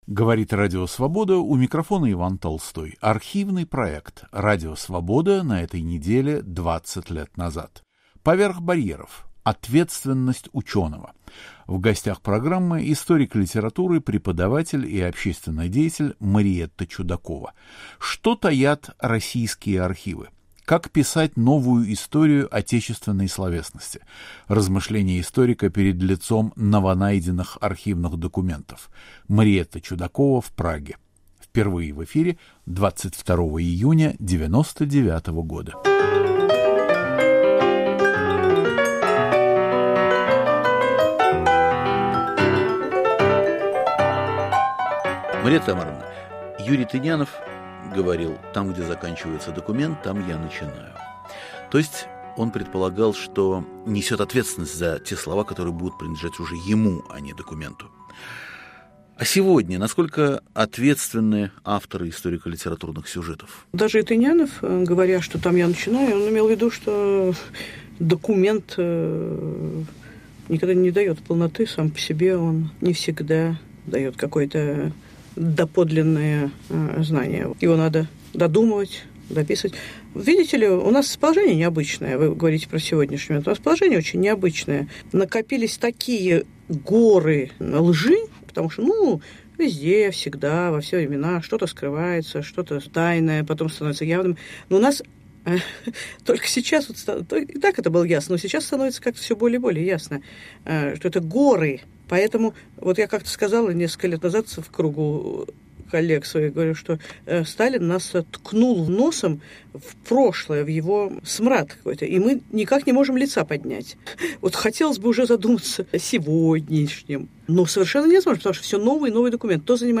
Писательница и литературовед Мариэтта Чудакова об ответственности ученого, о необходимости рассказать правдиво и полно о жестокой советской истории, об исчезновении Альфреда Бема, об Аркадии Белинкове и дневниках Михаила Булгакова. Автор и ведущий Иван Толстой.